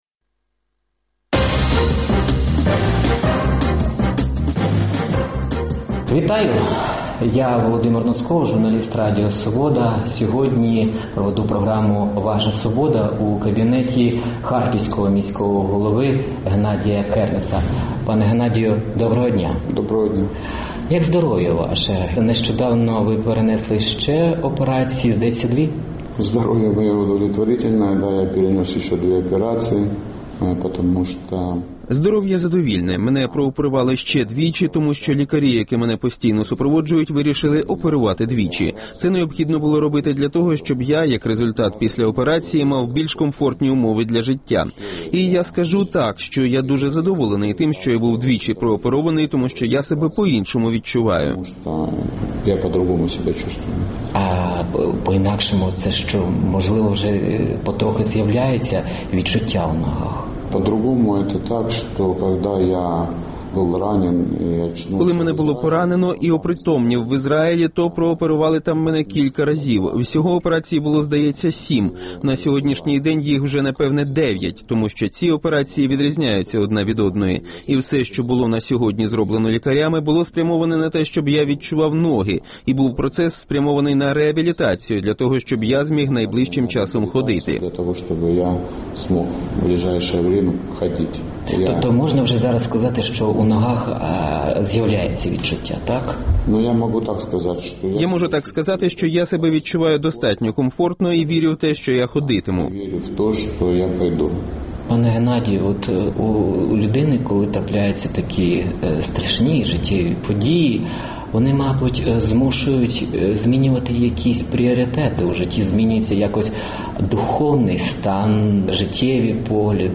Гість «Вашої Свободи»: мер Харкова Геннадій Кернес